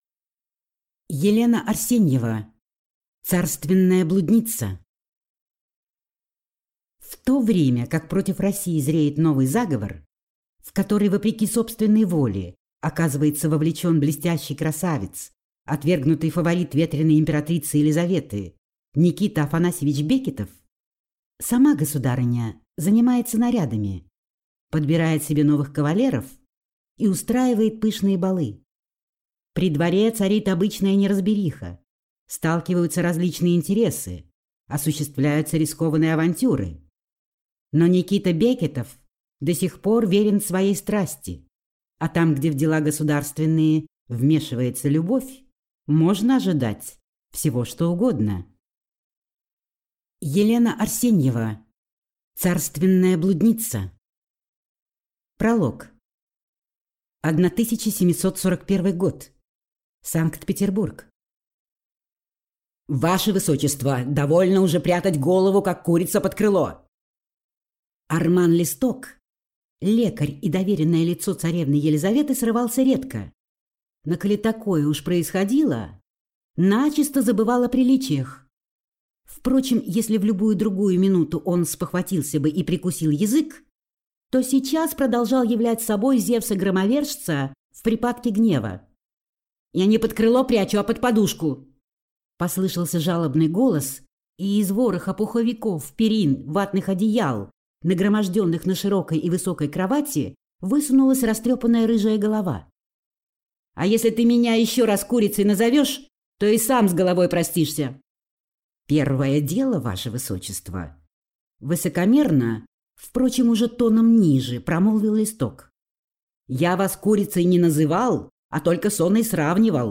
Аудиокнига Царственная блудница | Библиотека аудиокниг
Прослушать и бесплатно скачать фрагмент аудиокниги